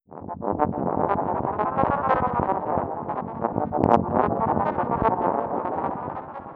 ominous.wav